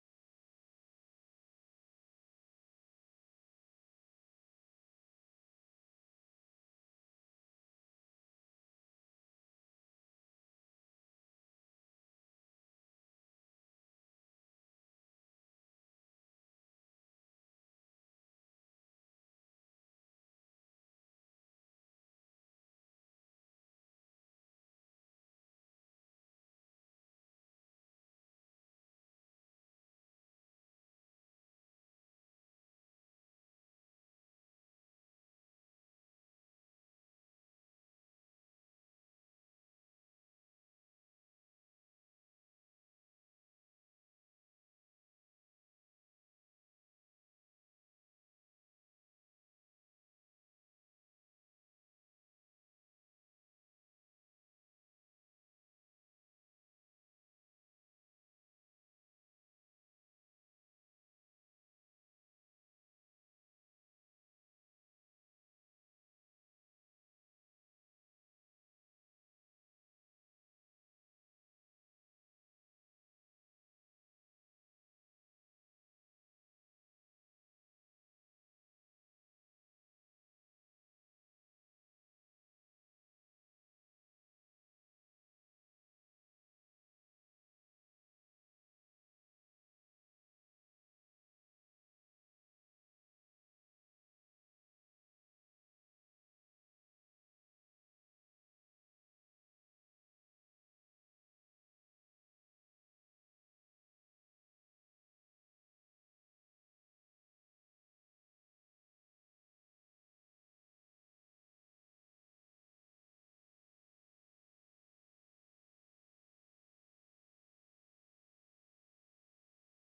recognize_silence_test.wav